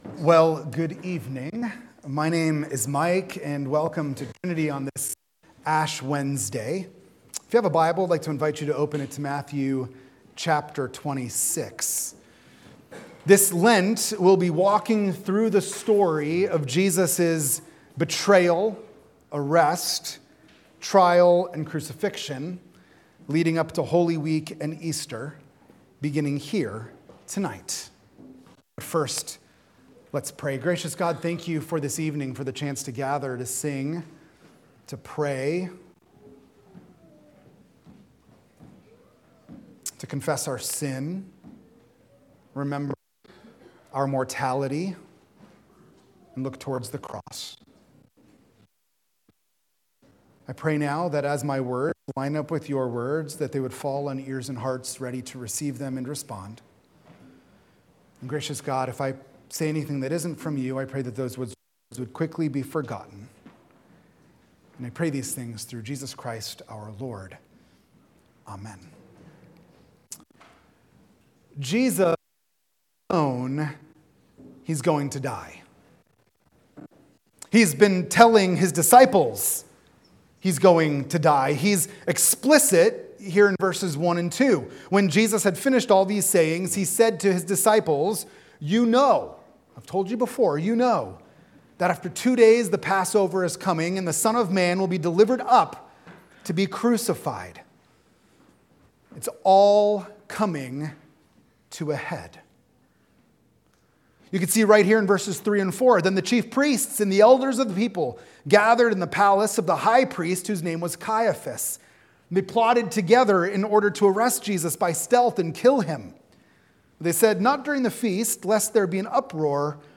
The-Way-of-the-Cross_-Ash-Wednesday.mp3